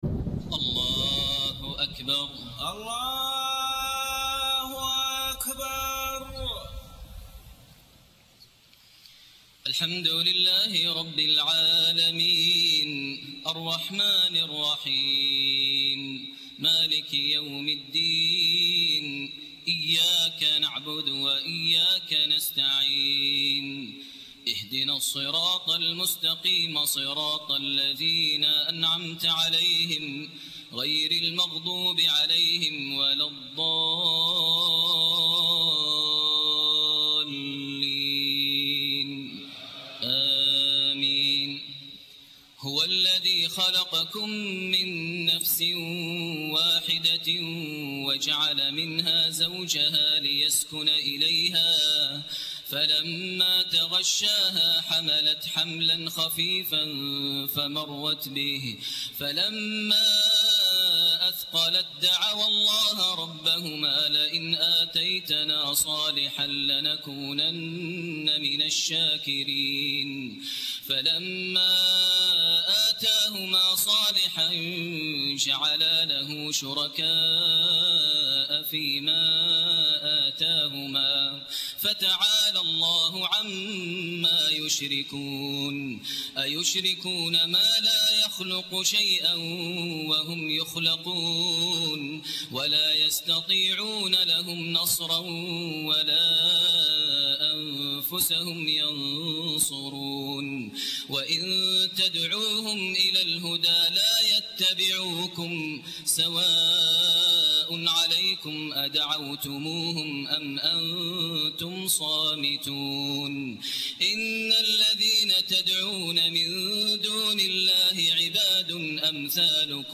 تهجد ليلة 29 رمضان 1429هـ من سورتي الأعراف (189-206) و الأنفال (1-40) > تراويح ١٤٢٩ > التراويح - تلاوات ماهر المعيقلي